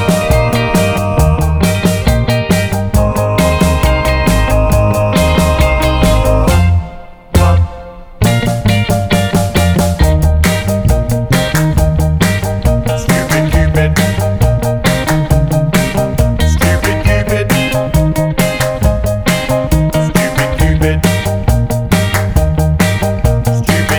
Minus Sax Pop (1950s) 2:14 Buy £1.50